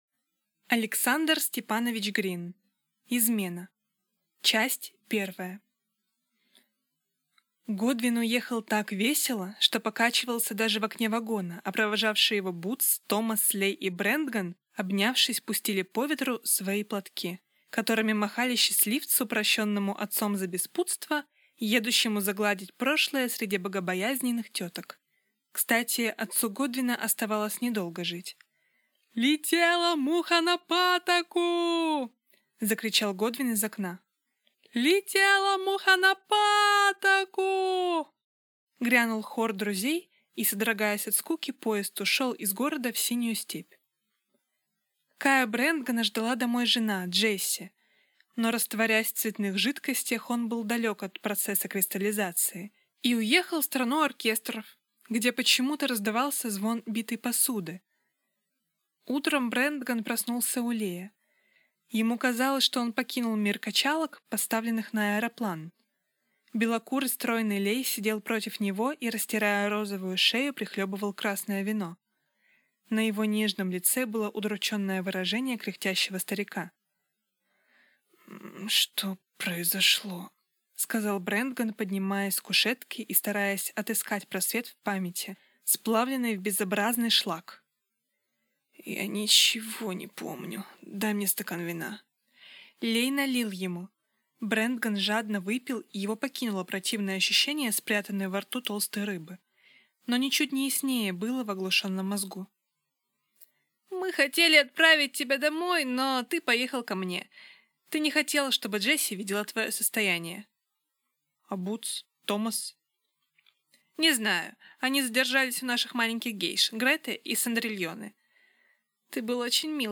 Аудиокнига Измена | Библиотека аудиокниг